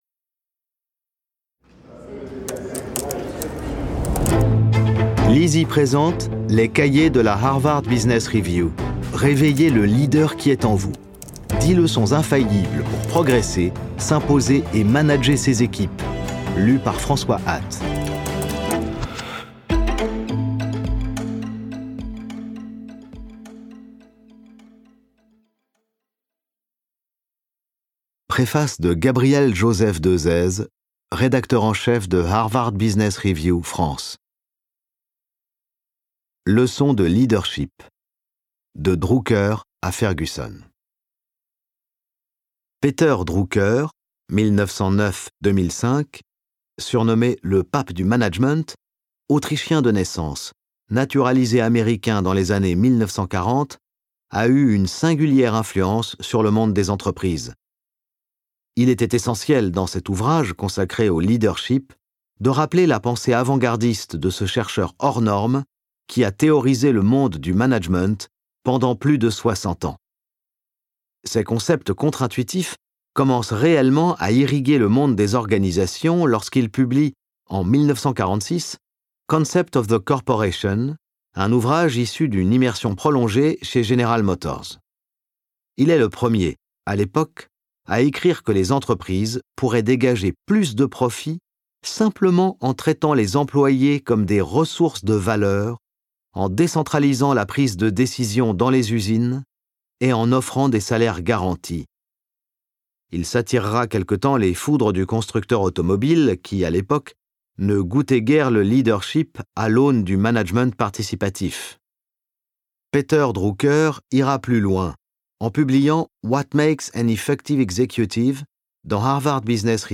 Ce livre audio fait partie de la collection Les Cahiers de la HBR .